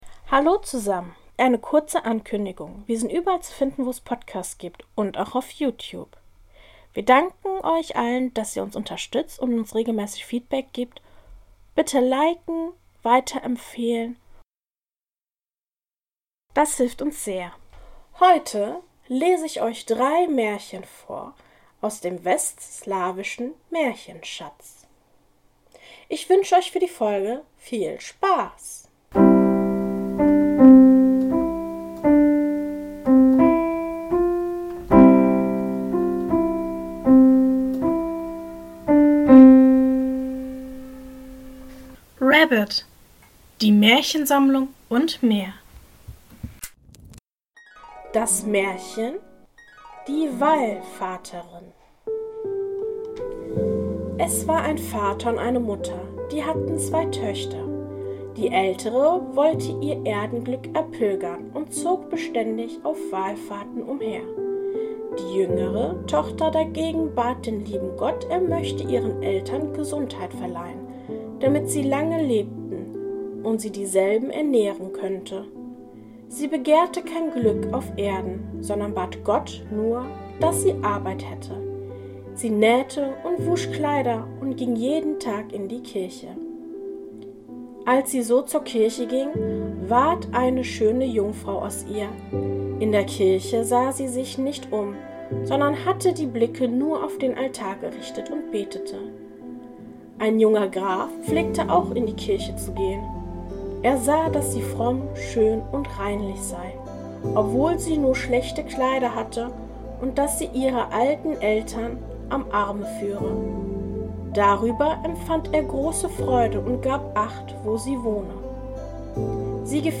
In diesem Podcast erzähle ich Euch verschiedene Märchen und möchte Euch einladen zu träumen und die Zeit gemeinsam zu genießen. Die Märchen werden aus aller Welt sein und sollen Euch verleiten, dem Alltag etwas zu entfliehen.